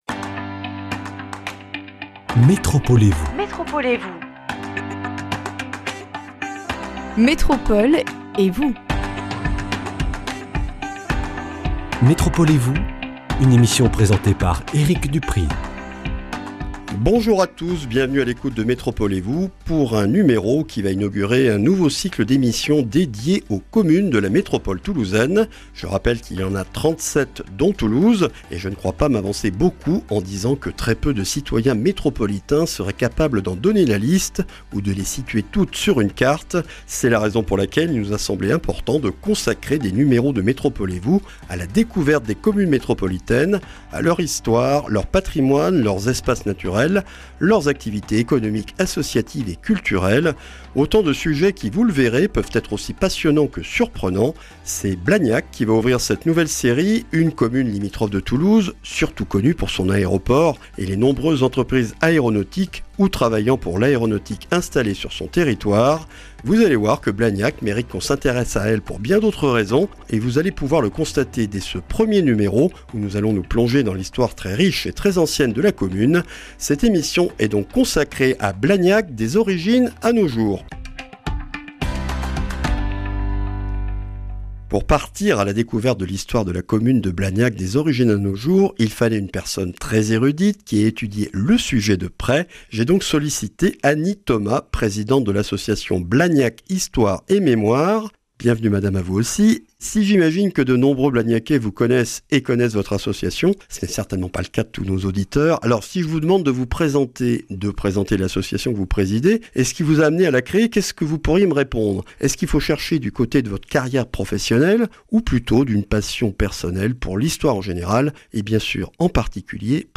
REDIFFUSION : Une émission consacrée à l’histoire et aux histoires de la commune de Blagnac